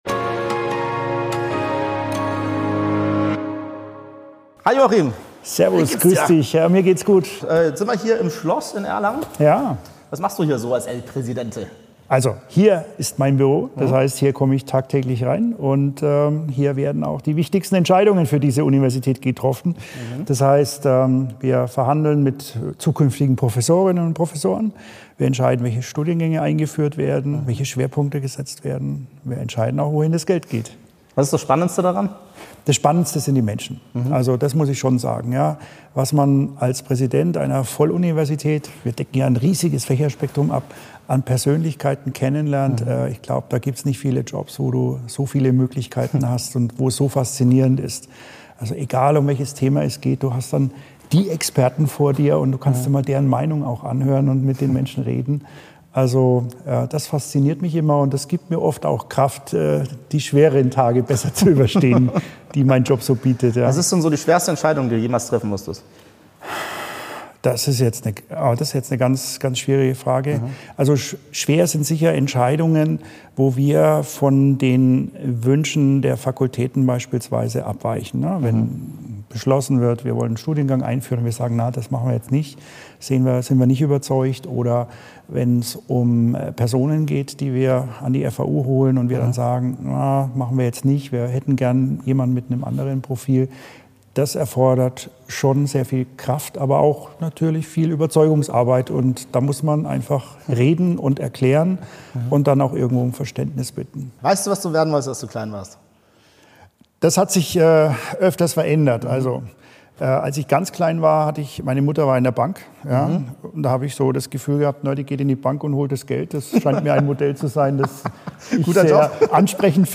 Das Interview hebt die Leidenschaft des Interviewten für Bildung, Führung und Innovation hervor.